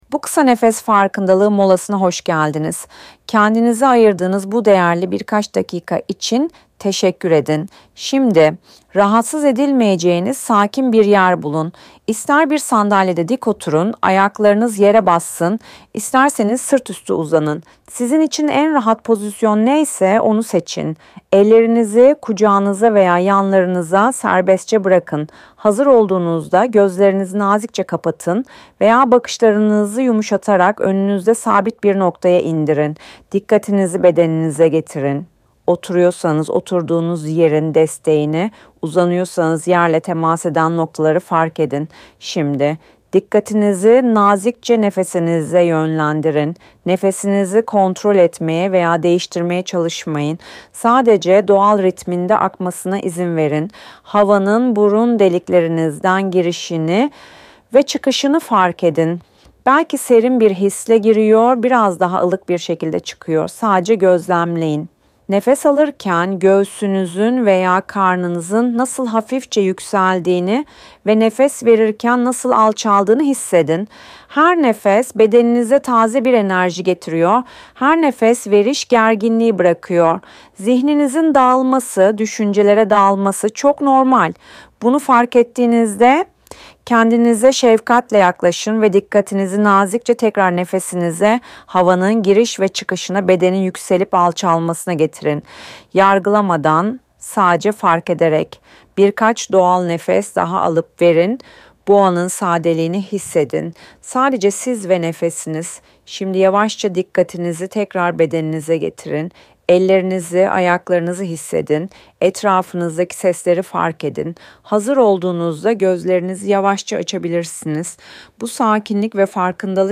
Farkındalık (Mindfulness) Meditasyonları Ses Kayıtları Paketi
kisa-nefes-farkindaligi.mp3